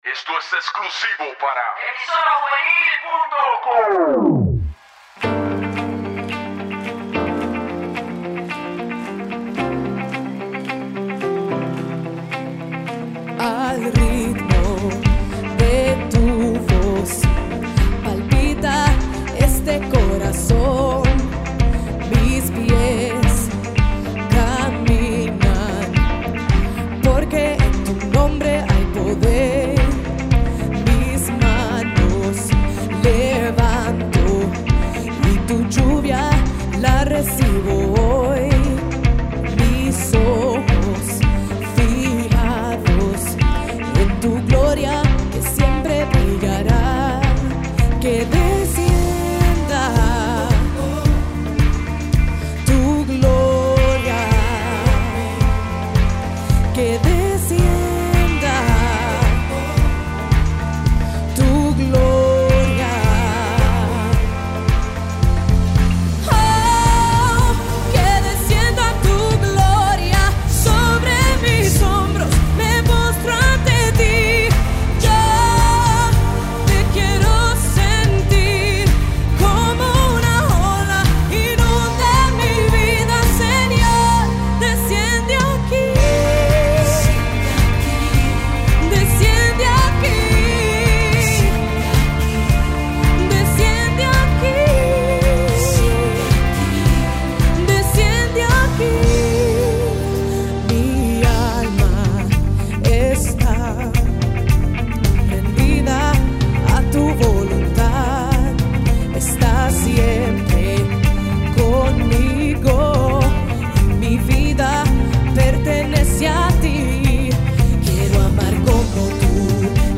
Musica Cristiana